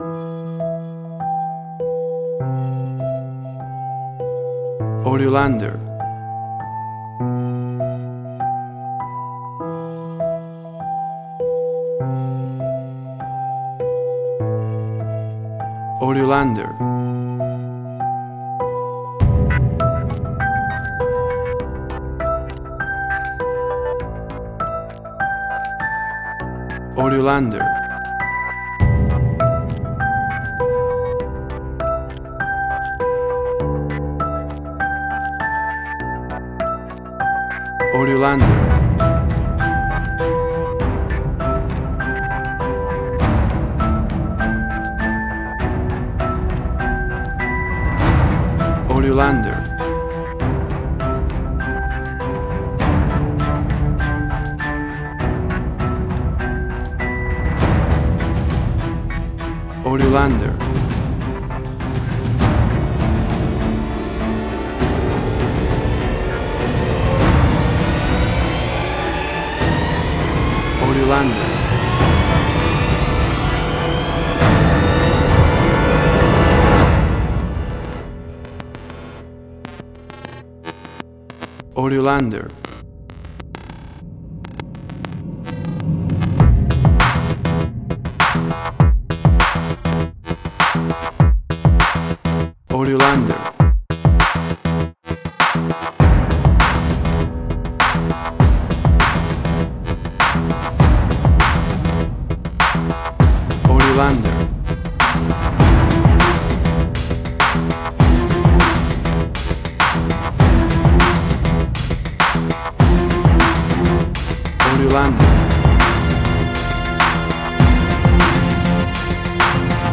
Suspense, Drama, Quirky, Emotional.
Tempo (BPM): 100